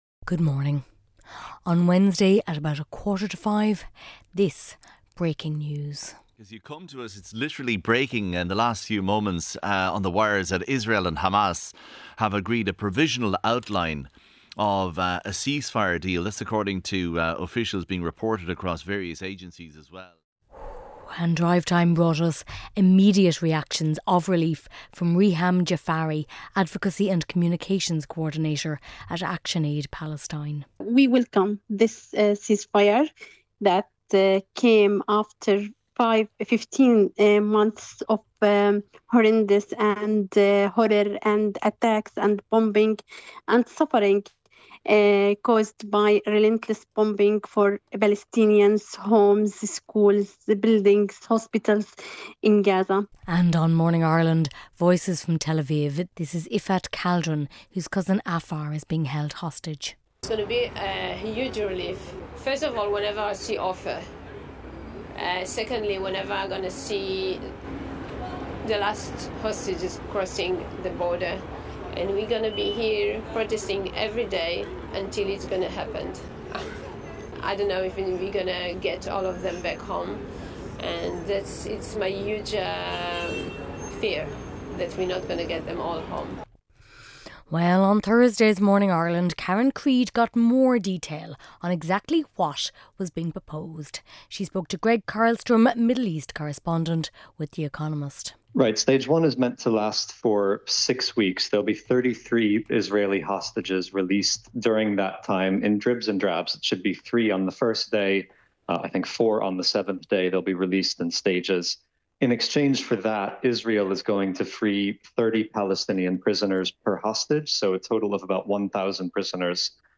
… continue reading 324 एपिसोडस # Ireland Current Affairs # Radio Programme # Ireland # RTÉ # International News # News # Society # RTÉ Radio 1